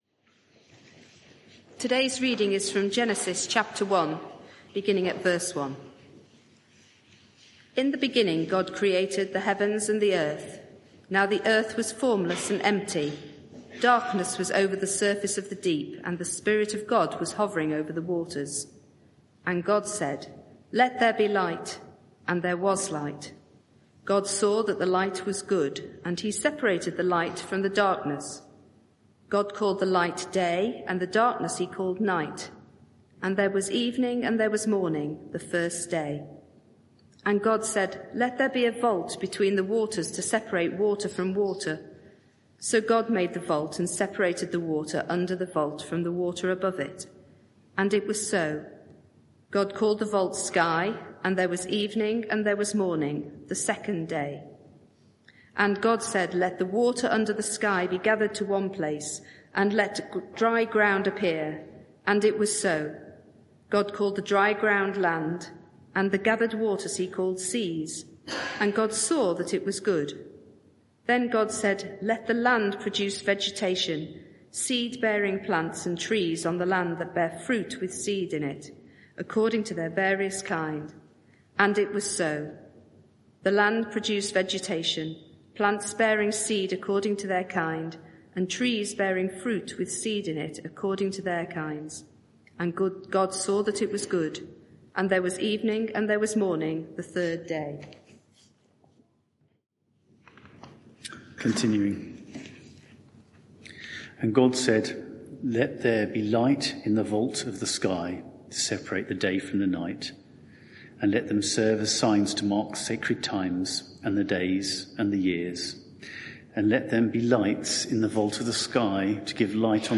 Media for 6:30pm Service on Sun 29th Sep 2024 18:30 Speaker
Passage: Genesis 1:1-25 Series: Life in God’s World Theme: Sermon (audio) Search the media library There are recordings here going back several years.